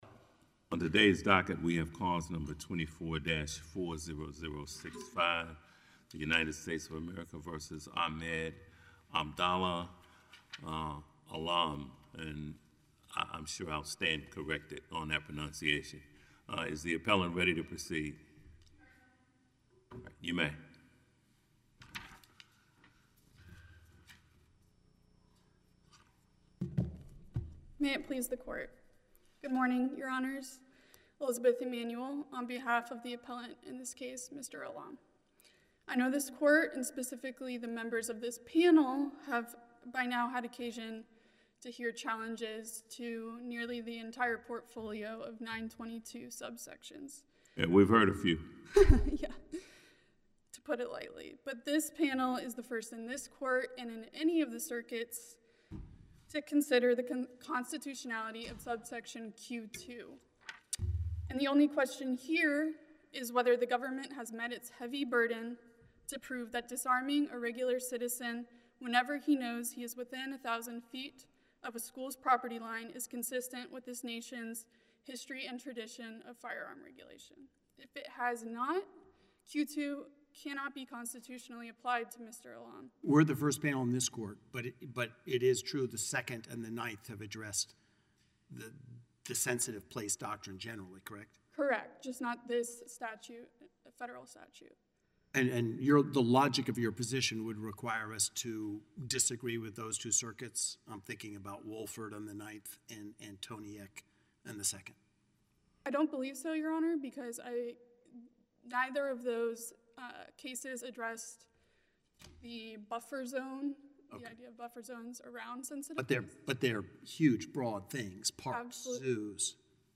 Oral Argument Recordings